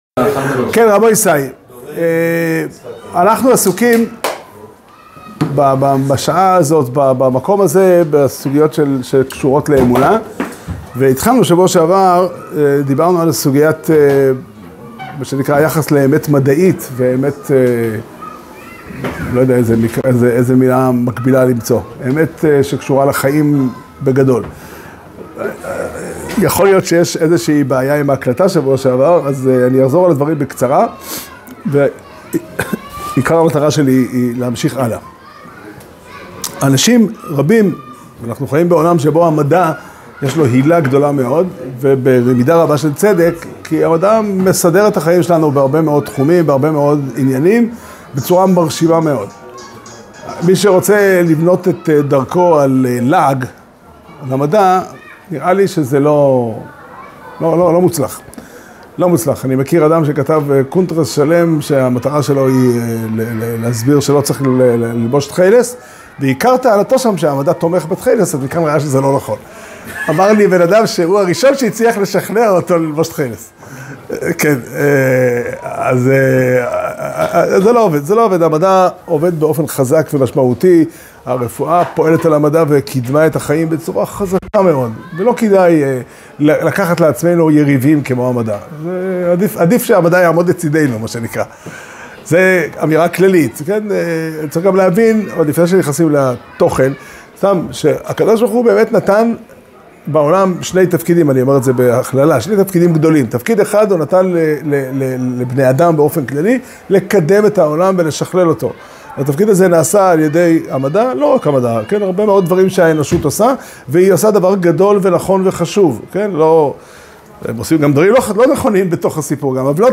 שיעור שנמסר בבית המדרש פתחי עולם בתאריך י"ז כסלו תשפ"ה